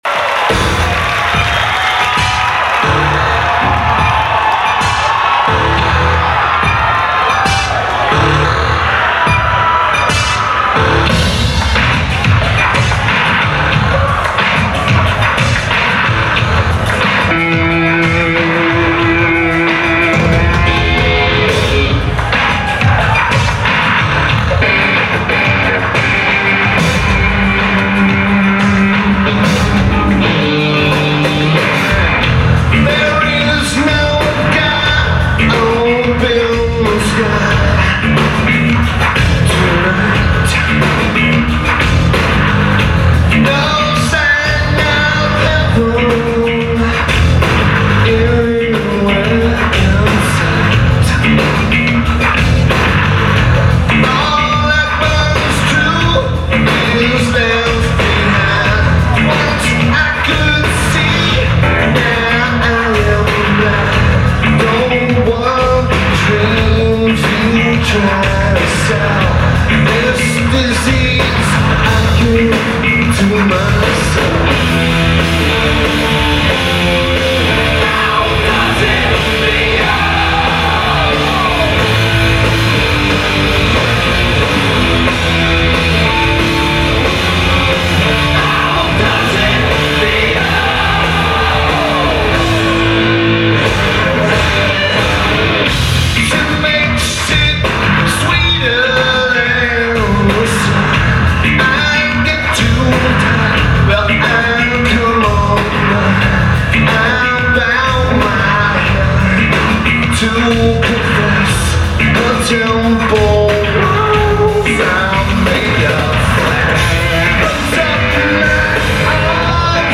Electric Factory